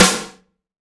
SNARE 060.wav